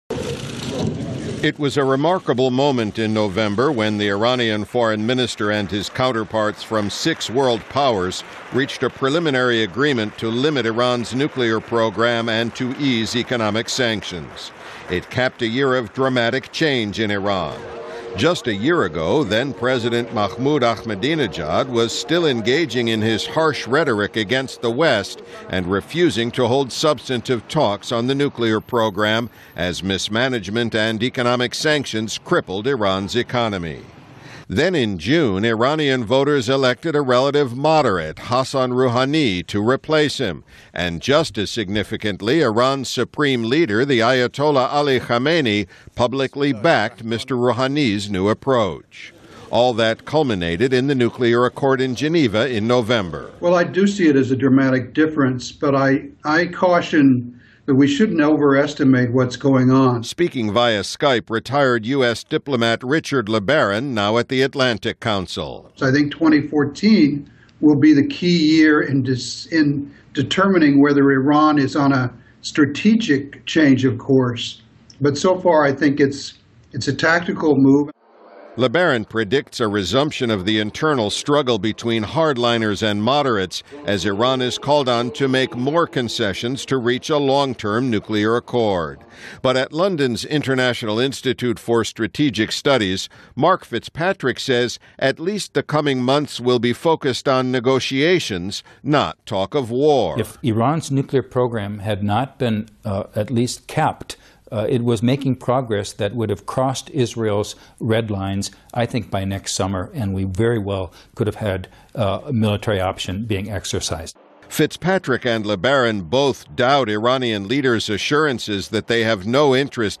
年终报道：伊朗2014年面临变革考验(VOA视频)